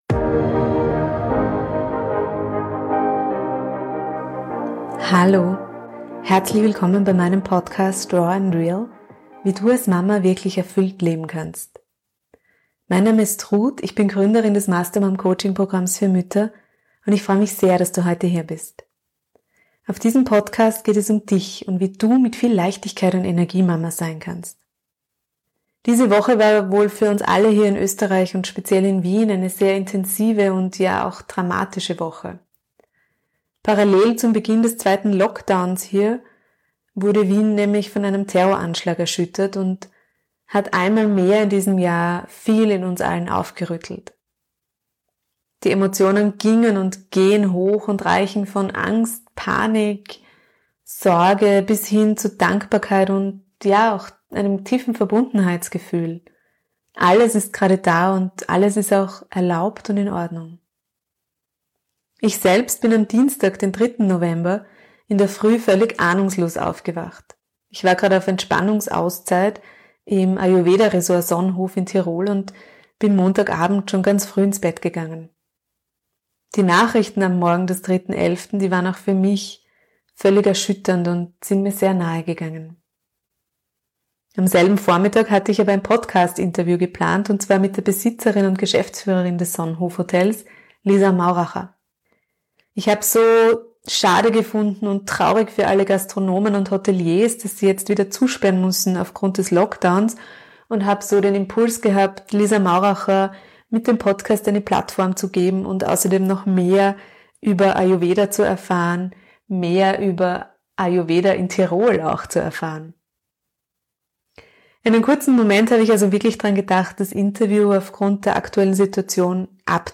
Ein sehr spannendes Interview mit einer bewegenden Frau Anmerkung: Unbezahlte Werbung wegen Nennung Wenn dir dieser Podcast gefällt, dann hinterlasse doch bitte eine 5-Sterne-Bewertung, dein Feedback und abonniere diesen Podcast.